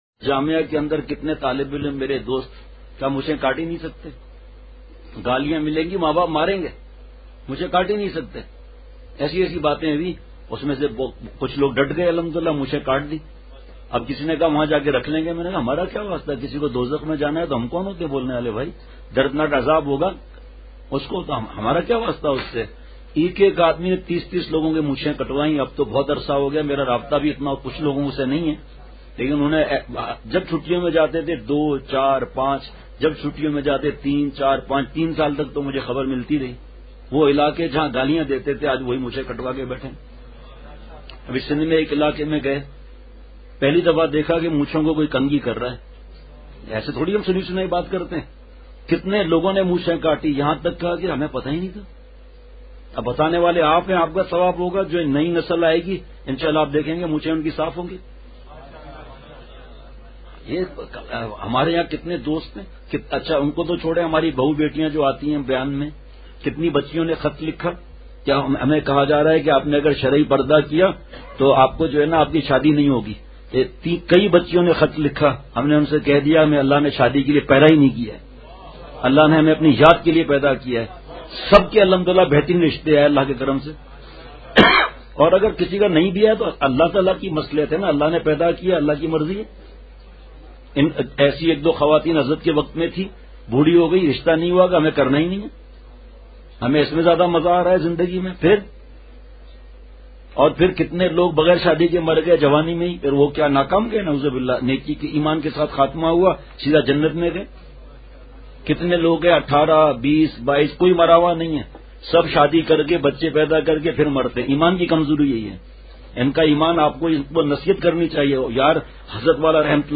اصلاحی مجلس کی جھلکیاں